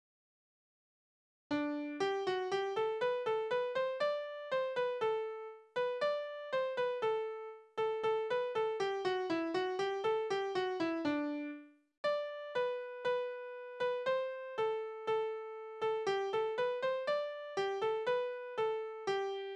Balladen: Es blies ein Jäger wohl in sein Horn
Tonart: G-Dur
Taktart: 4/4
Tonumfang: Oktave
Besetzung: vokal